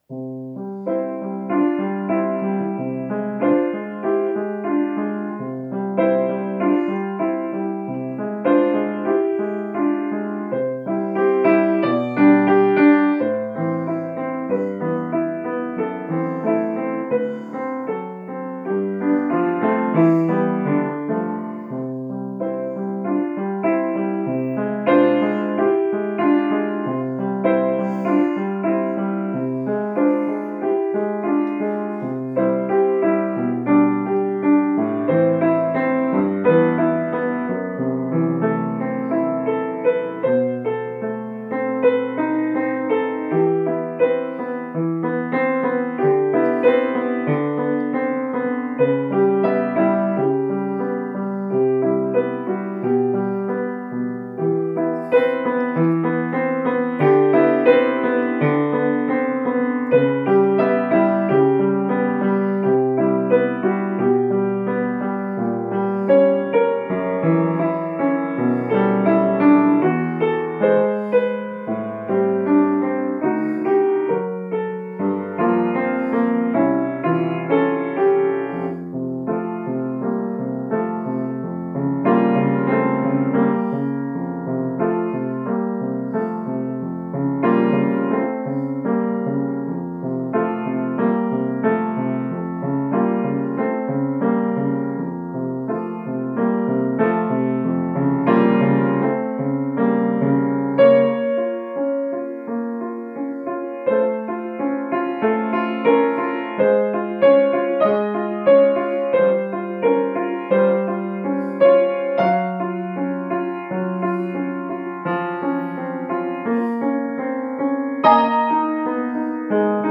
Klavier